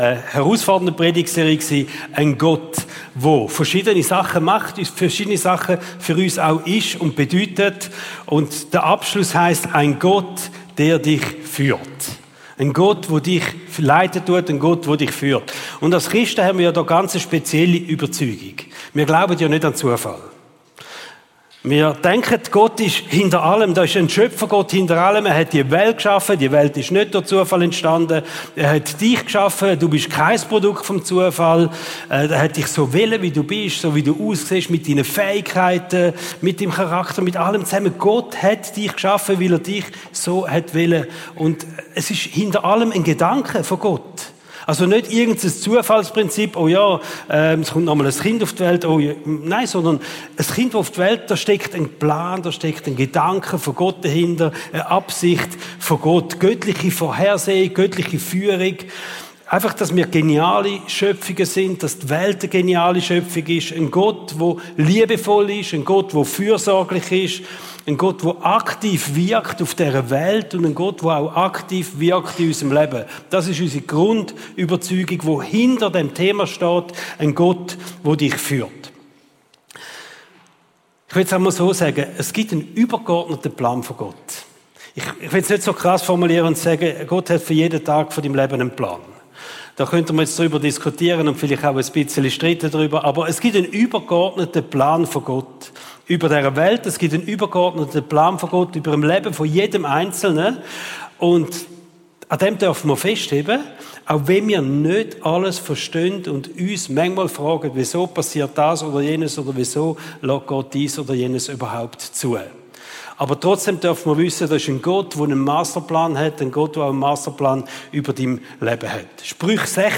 Regelmässig die neusten Predigten der GvC Frauenfeld